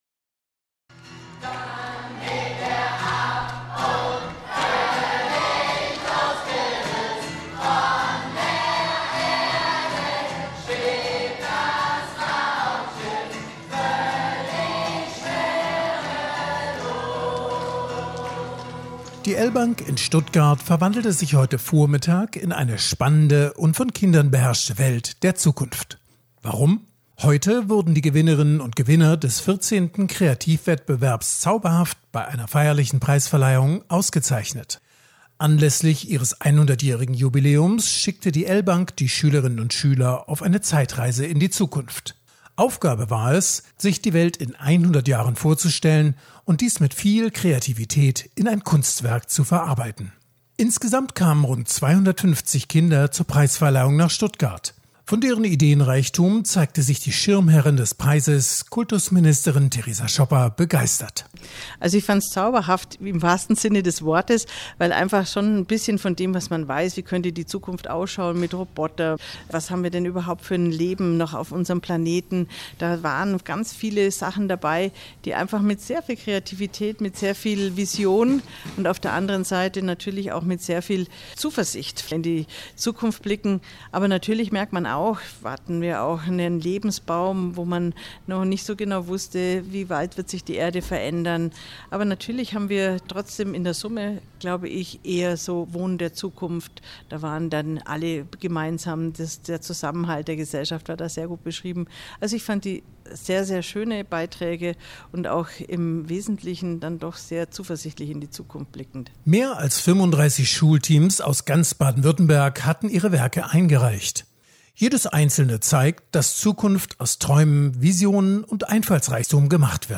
Sendefähige Interviews, Statements und O-Töne zu aktuellen Themen
• Radio-Feature der Preisverleihung Zauberhaft am 15.07.2024